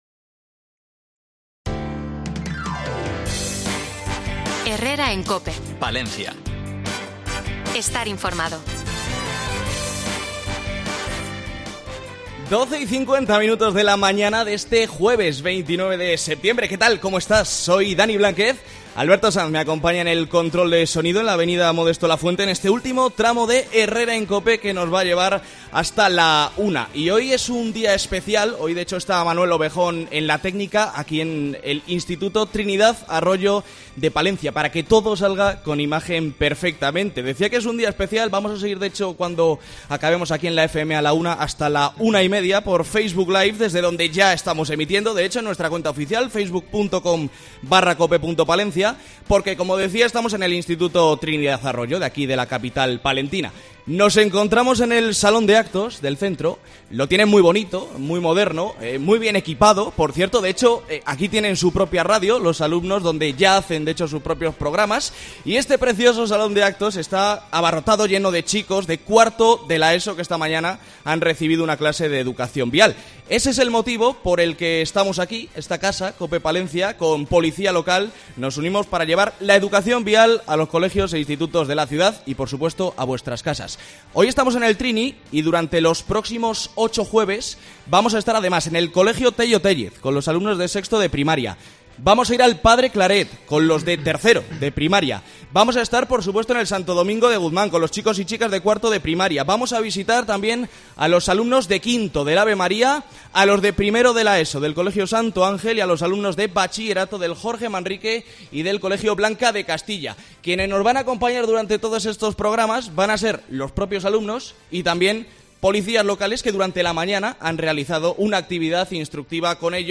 Especial Herrera en Cope Palencia 12,50 Horas Educación Vial hoy desde el Instituto Trinidad Arroyo, con la colaboración del Ayuntamiento de Palencia y la Policia Local de Palencia.